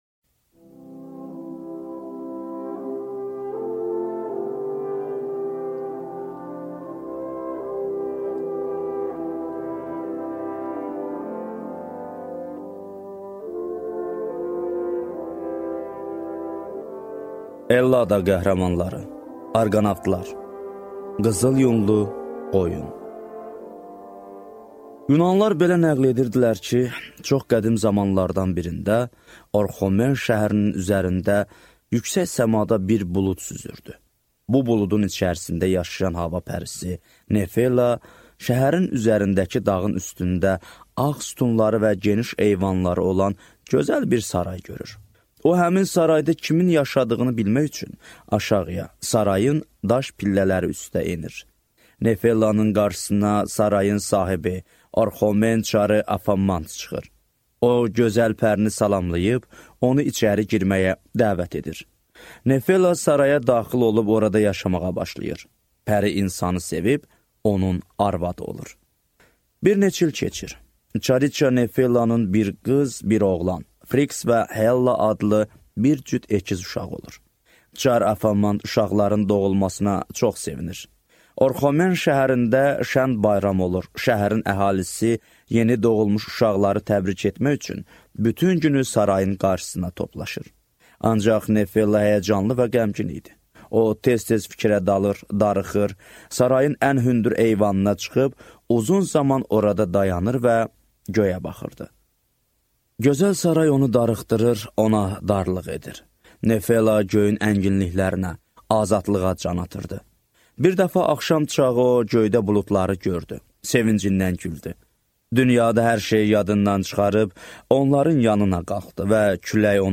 Аудиокнига Ellada qəhrəmanları | Библиотека аудиокниг